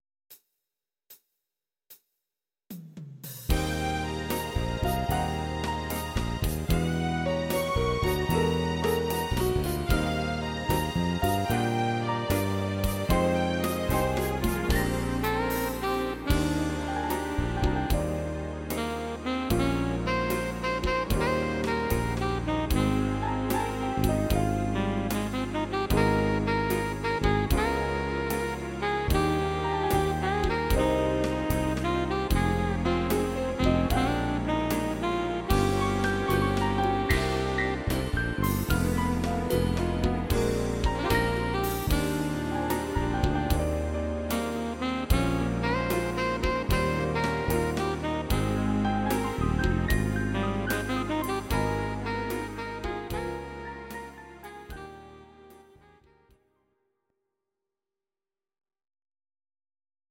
Saxophon